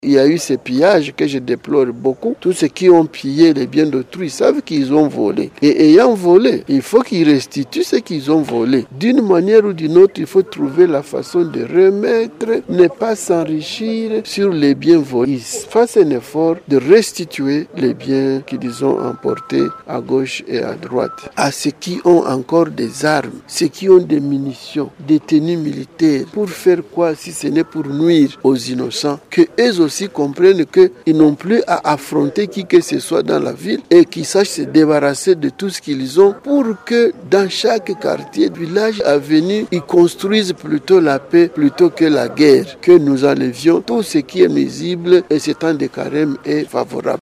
Il l’a dit dans une interview accordée à la presse après la messe solennelle d’ouverture du Temps Fort du Carême soit le Mercredi de Cendres, une messe dite en la cathédrale Notre Dame de la Paix de Bukavu en commune d’IBANDA.